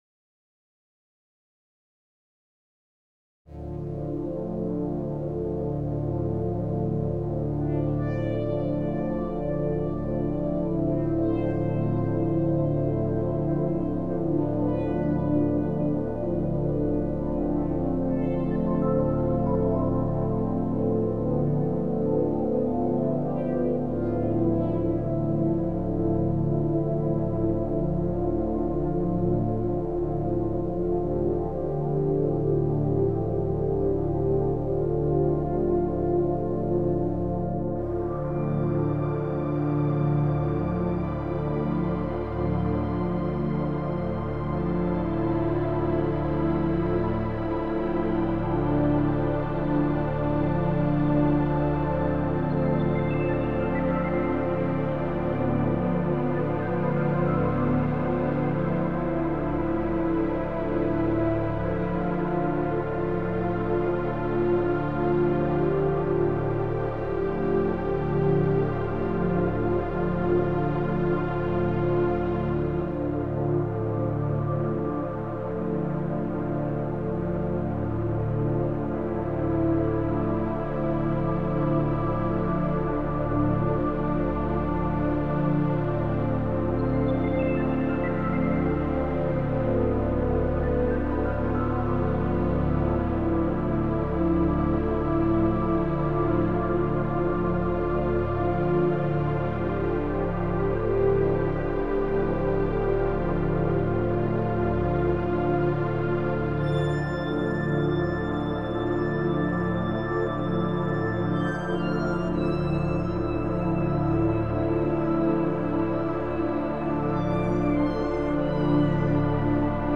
/ Electronic